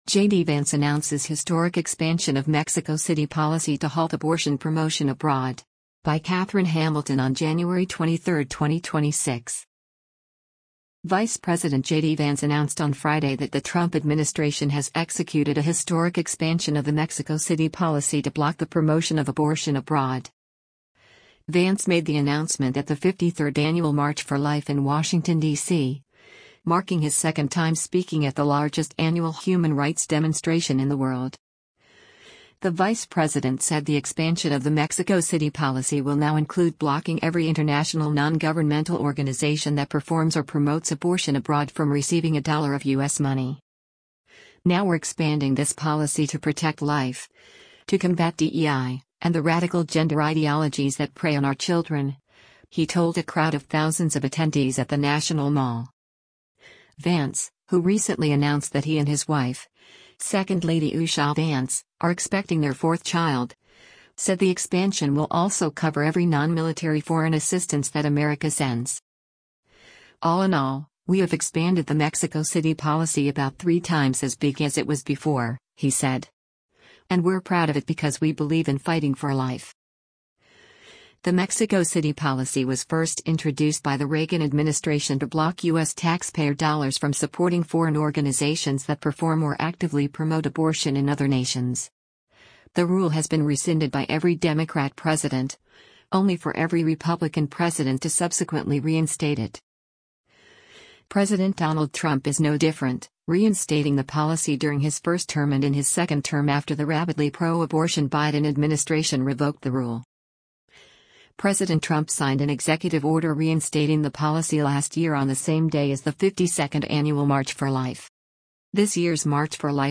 Vance made the announcement at the 53rd annual March for Life in Washington, DC, marking his second time speaking at the largest annual human rights demonstration in the world.
“Now we’re expanding this policy to protect life, to combat DEI, and the radical gender ideologies that prey on our children,” he told a crowd of thousands of attendees at the National Mall.